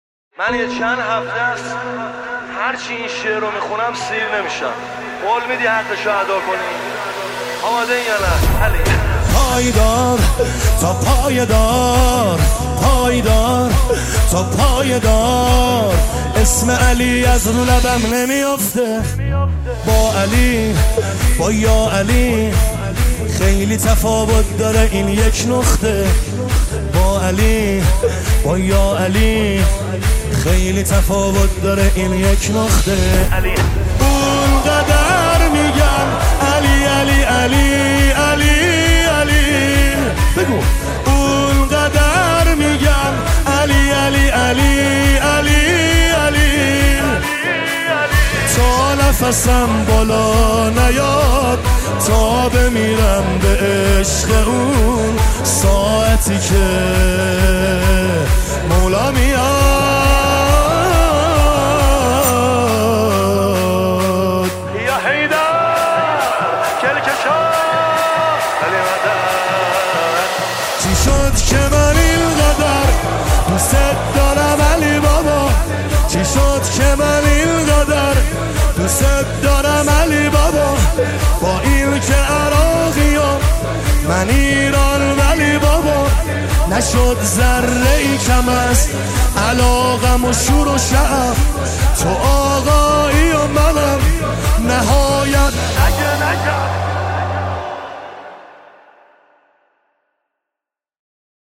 مداحی های منتخب
عید غدیر خم